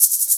Shaker 02.wav